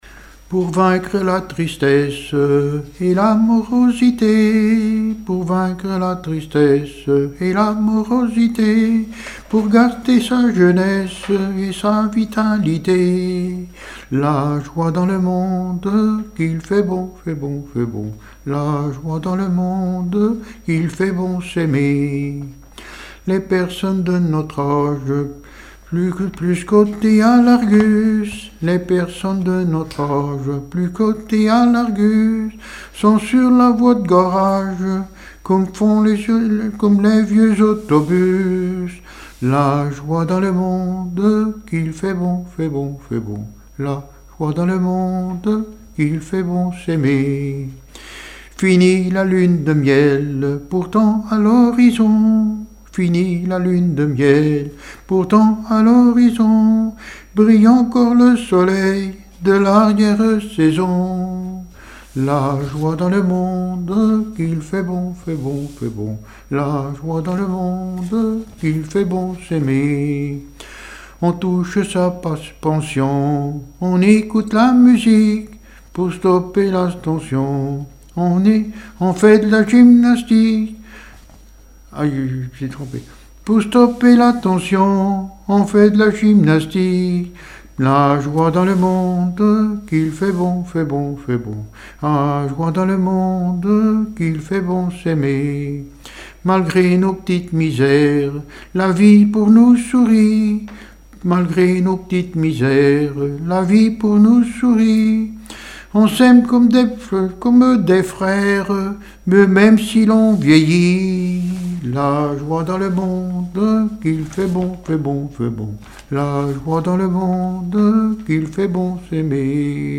chanson du club des retraités
Témoignages et chansons
Pièce musicale inédite